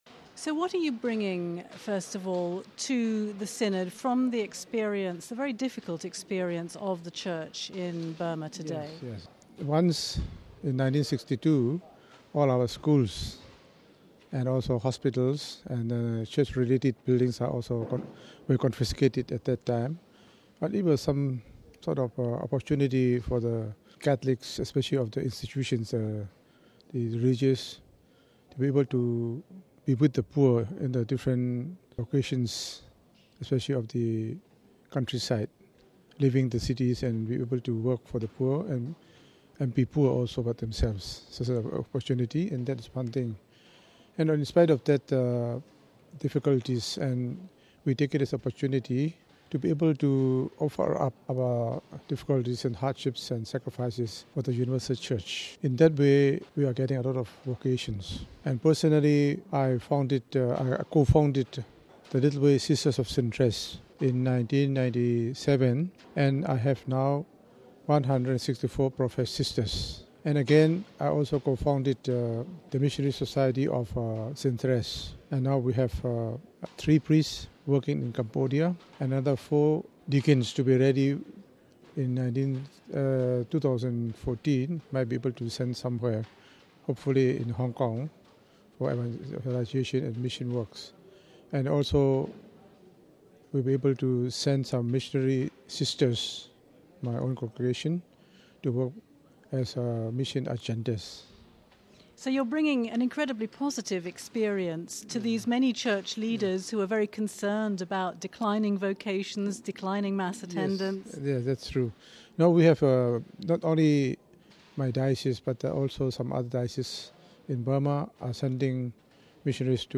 the quietly spoken prelate reveals that community has grown in numbers and in faith despite decades of repression under a military dictatorship. He says that since the expropriation of all Church property by the regime in 1962, Burmese Catholics have sought to live in such a way as to ensure their survival.